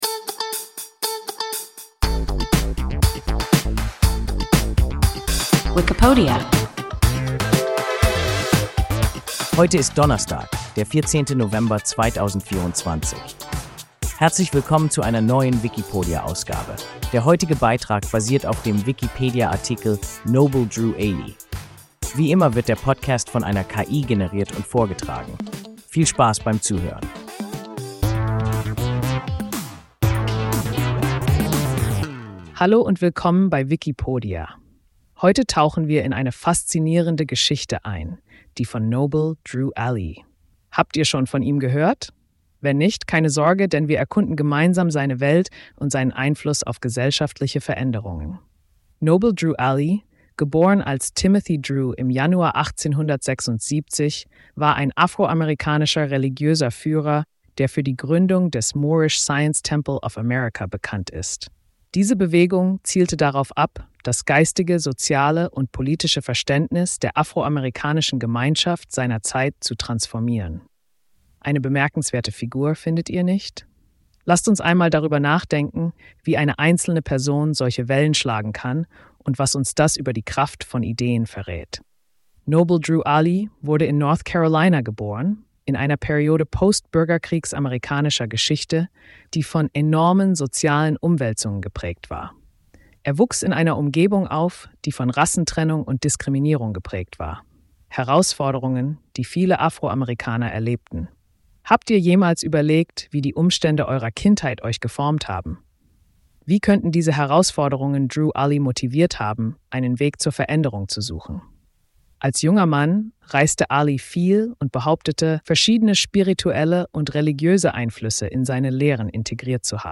Noble Drew Ali – WIKIPODIA – ein KI Podcast